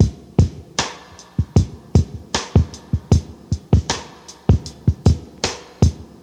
77 Bpm Drum Groove G Key.wav
Free drum groove - kick tuned to the G note. Loudest frequency: 707Hz
.WAV .MP3 .OGG 0:00 / 0:06 Type Wav Duration 0:06 Size 1,05 MB Samplerate 44100 Hz Bitdepth 16 Channels Stereo Free drum groove - kick tuned to the G note.
77-bpm-drum-groove-g-key-3Ug.ogg